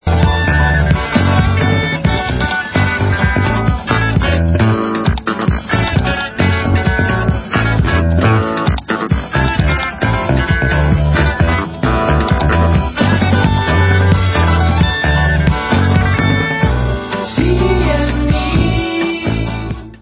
Rockové základy byly doplněny elektronickými klávesami
Bonusy rozšiřují obsazení o další muzikanty